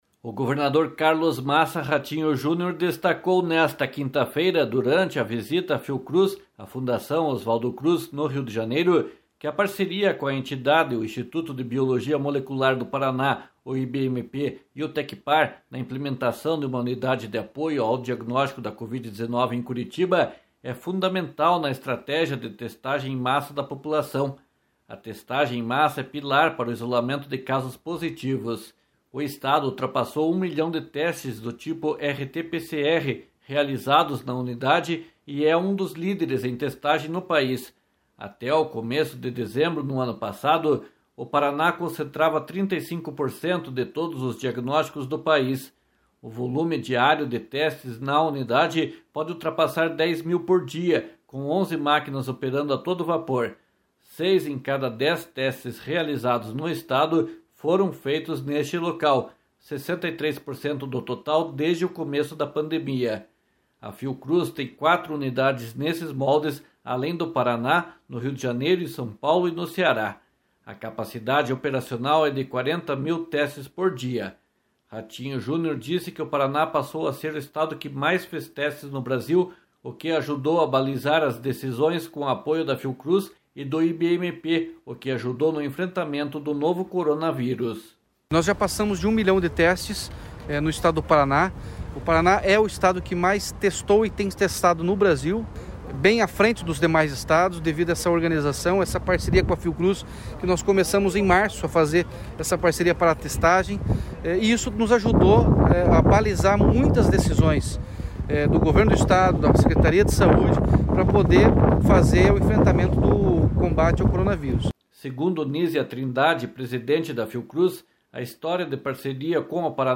//SONORA RATINHO JUNIOR//
//SONORA NÍSIA TRINDADE//